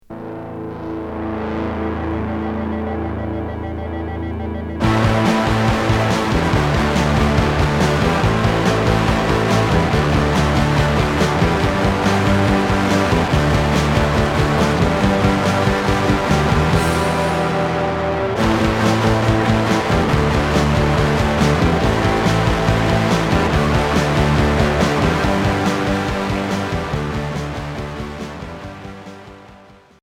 Rock indé